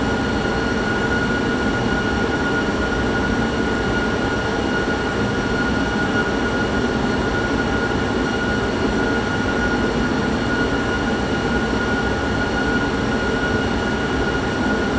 v2500-lowspool.wav